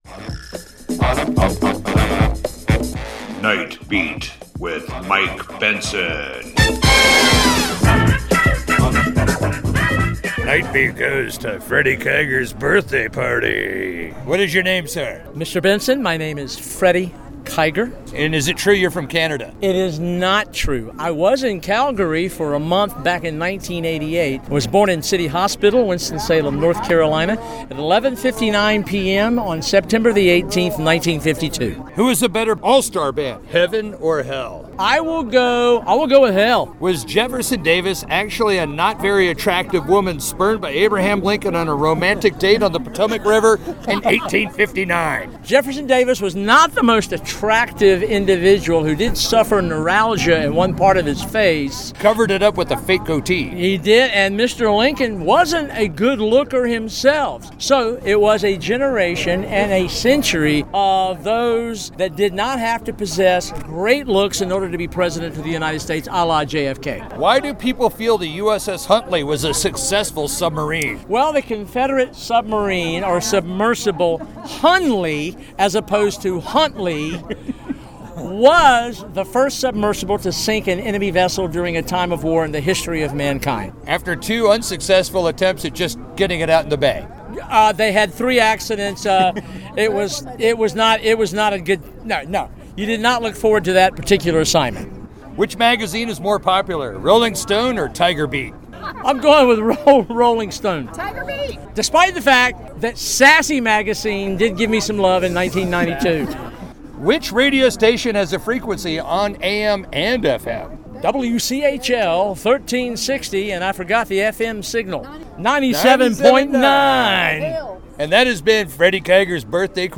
live and on the scene